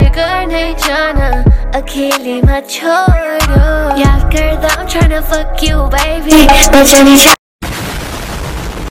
Crunchy Audio warning 🦨💨 sound effects free download